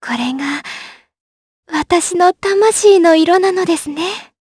Talisha-Vox_awk2_Jp.wav